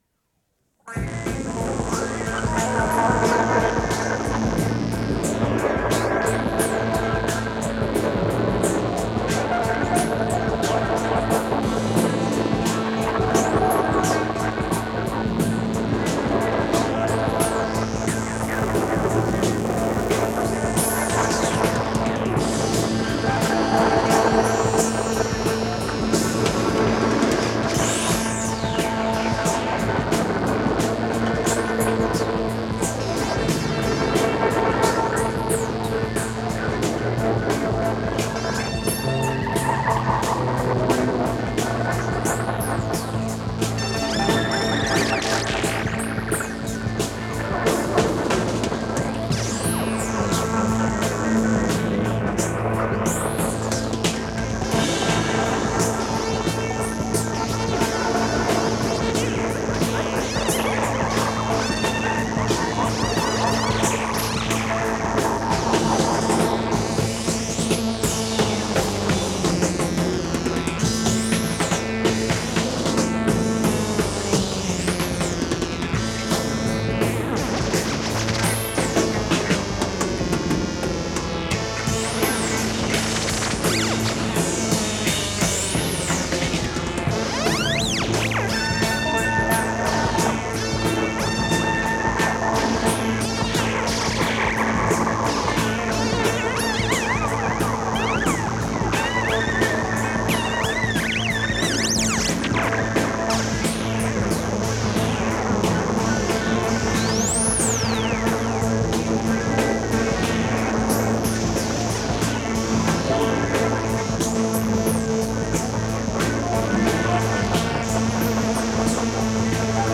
Жанр: Rock, Pop
Стиль: Art Rock, Ballad, Vocal, Classic Rock
Вокальная сюита
флейта, саксофон